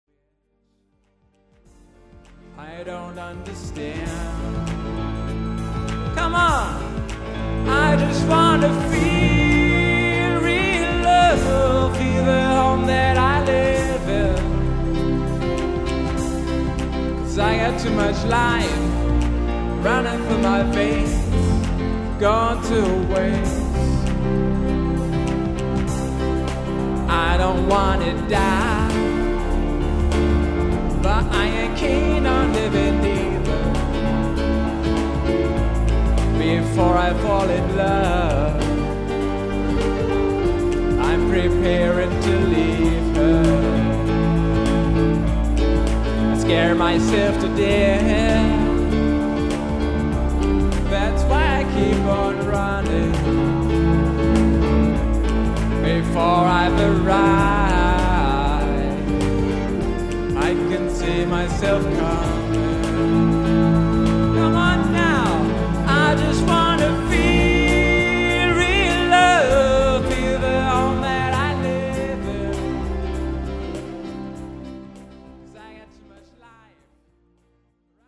Piano & Vocals (am Keyboard und Klavier)
Mitreißend: